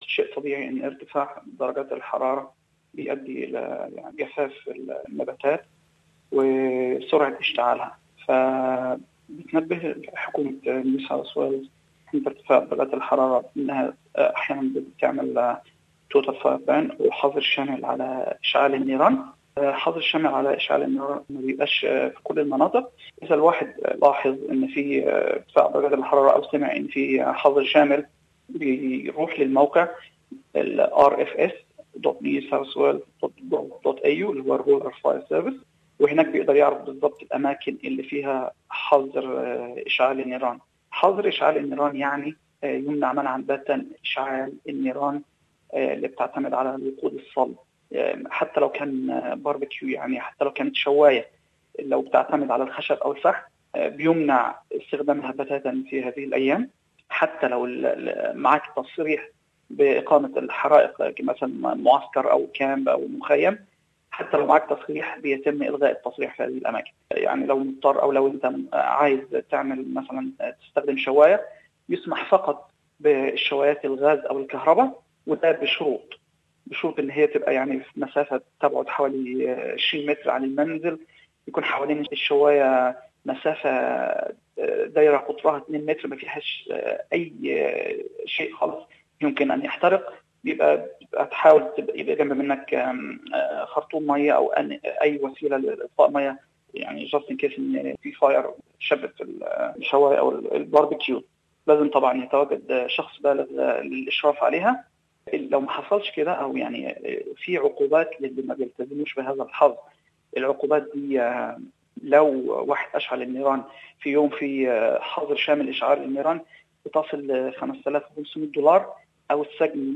المزيد من هذه النصائح في المقابلة التالية